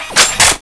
shootb_on1.wav